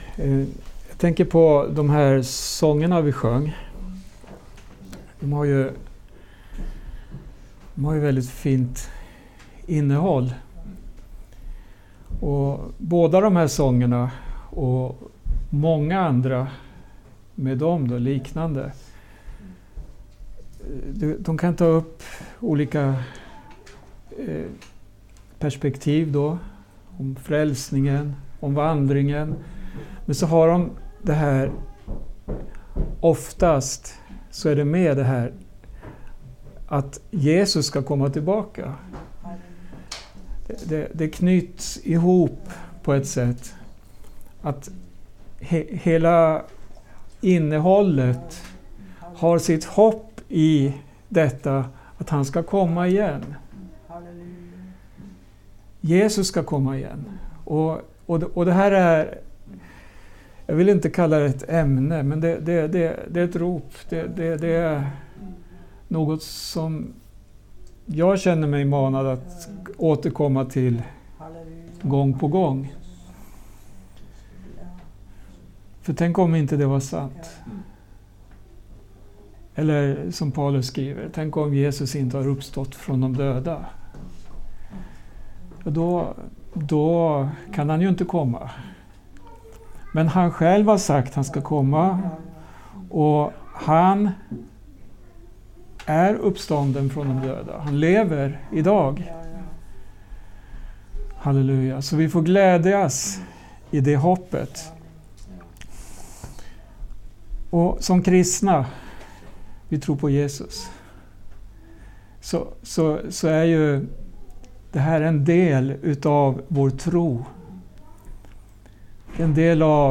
Inspelat hos församlingen i Skälby, Järfälla 14 maj 2025